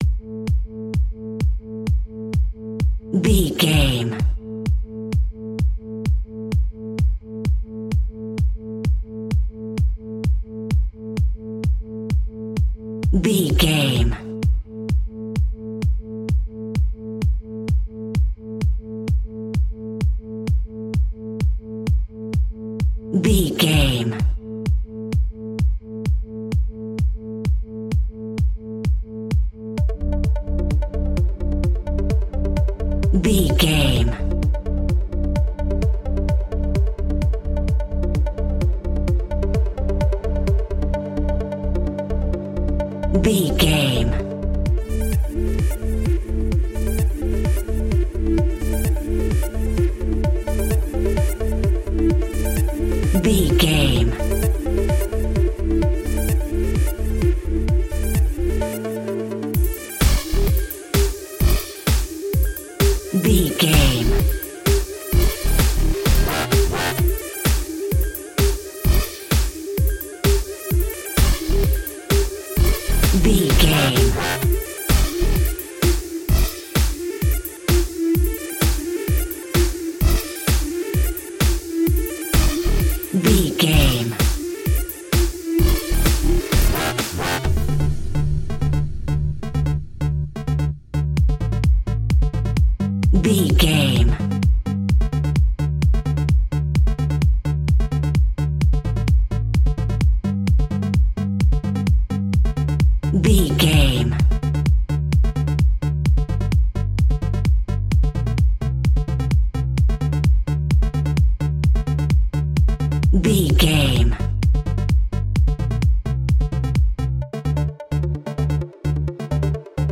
Aeolian/Minor
Fast
aggressive
dark
groovy
frantic
drum machine
synthesiser
breakbeat
energetic
instrumentals
synth leads
synth bass